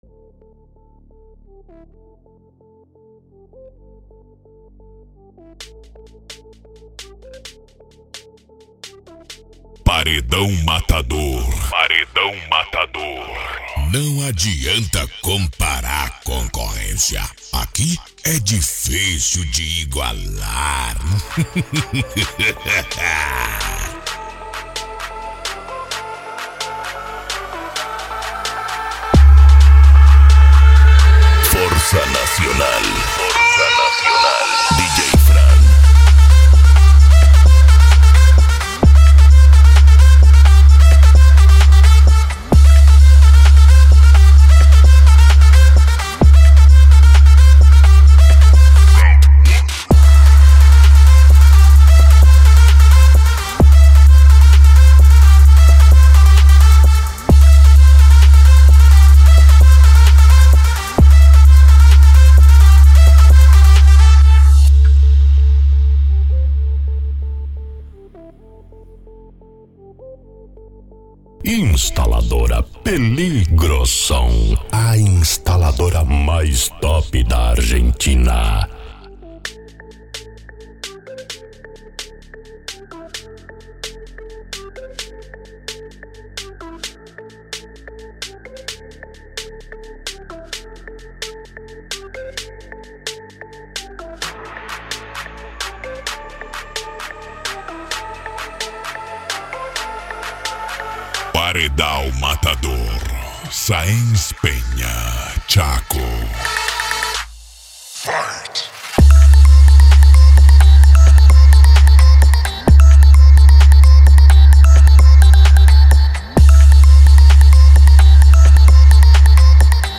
Bass
Mega Funk
Psy Trance